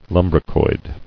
[lum·bri·coid]